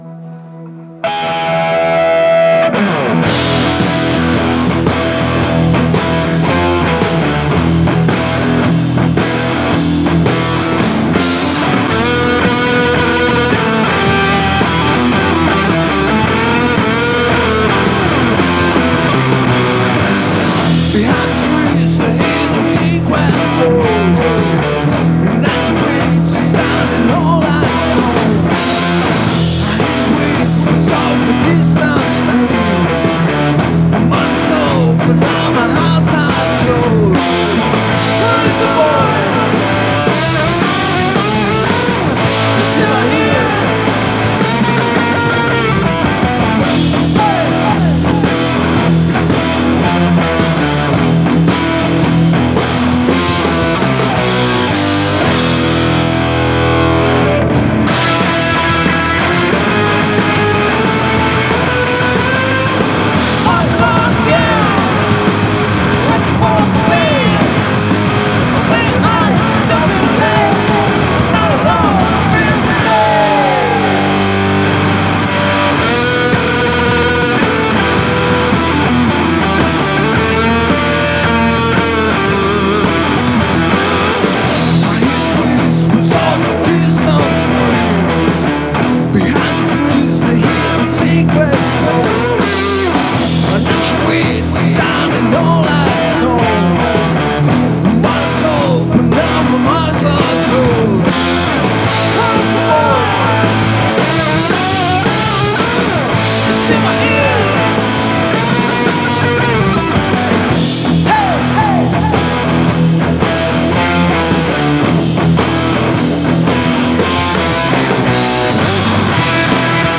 Argentine/Spanish/Indie/garage/alternative band
Indie with a Spanish accent.